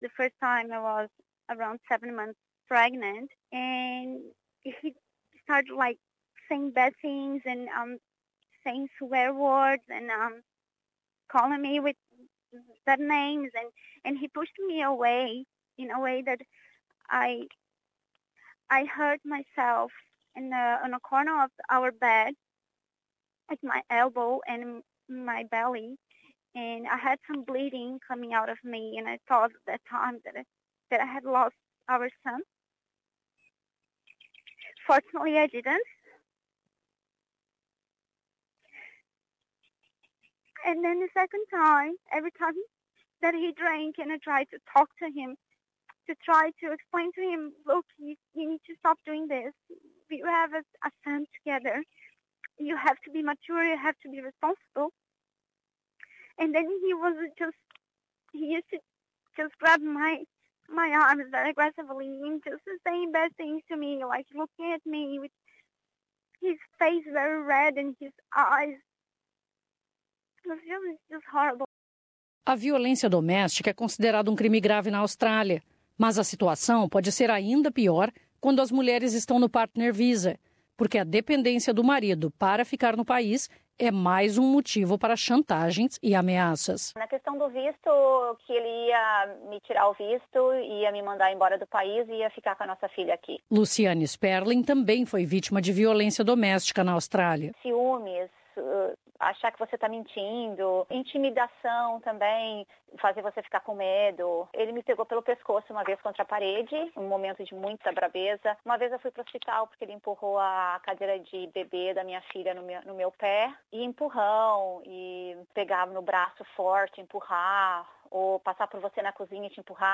Ela foi agredida com sete meses de gravidez, e conta sua história chorando nesta entrevista em inglês para a Rádio SBS.
Por questões de segurança, ela preferiu não se identificar, assim como "Ingrid", que teve o nome trocado e a voz alterada para essa entrevista.